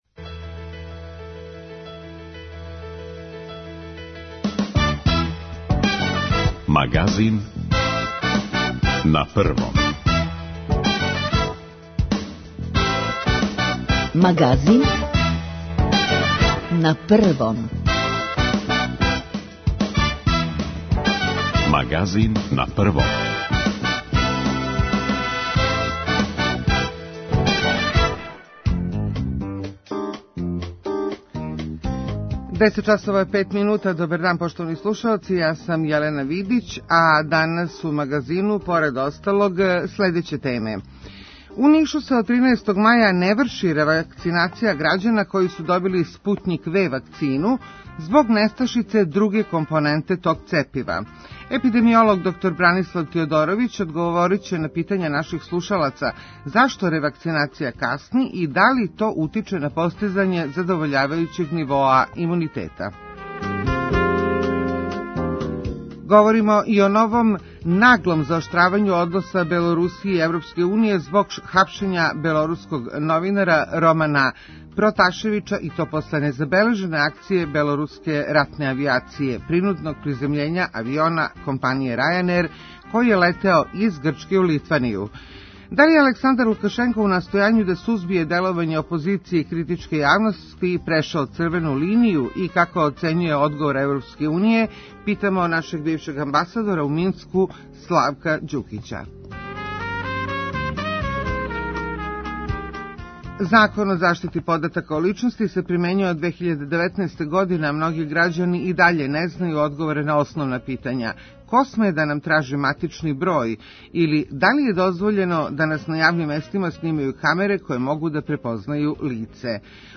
За Магазин говори Повереник за заштиту података о личности Милан Мариновић.